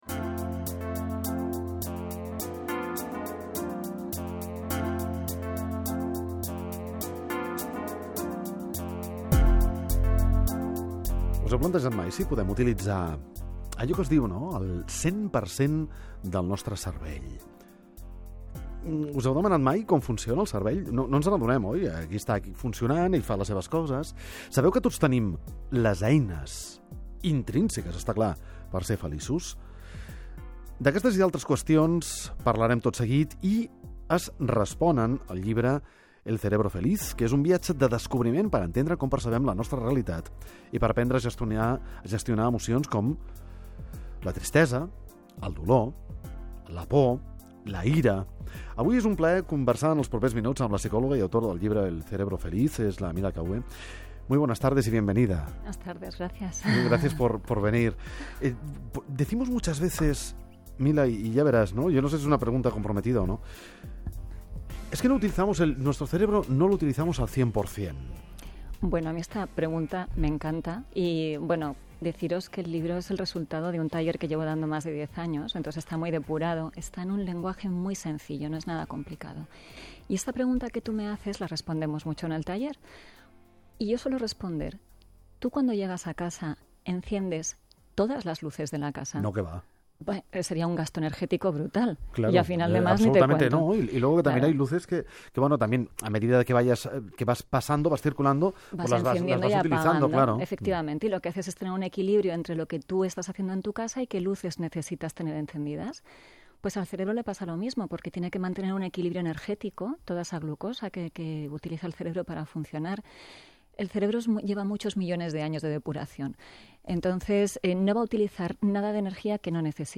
En los estudios de Radio Nacional en Barcelona, pasamos un rato muy, muy agradable hablando del Cerebro Feliz. Una entrevista que tenía muchas ganas de compartir con vosotros porque me pareció una auténtica delicia y lo pasamos muy bien.